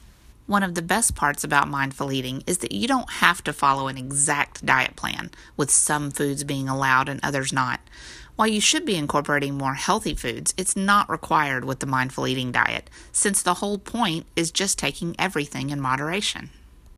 I can hear a big difference between how I was reading scripts before and how I do it now.
Now listen to it after about 3 lessons:
I sound less robotic and I hear emphasis on different words and phrases.